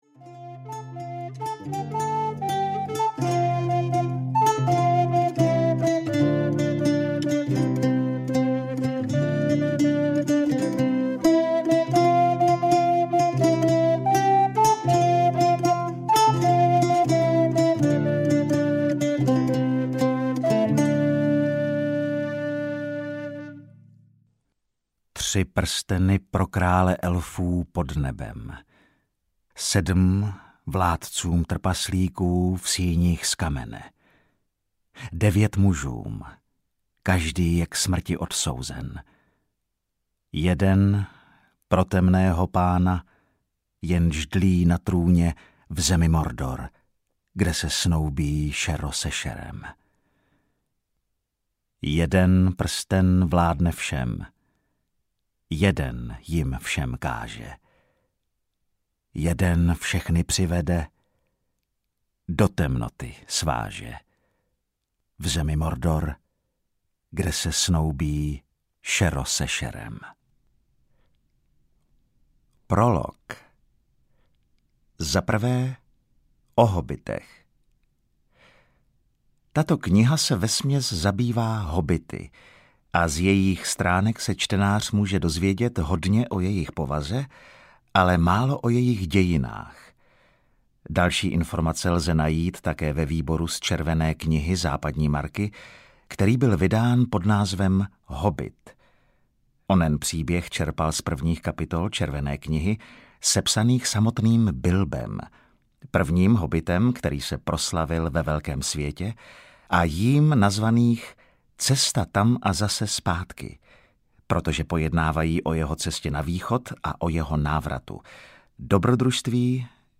Pán prstenů (trilogie) audiokniha
Ukázka z knihy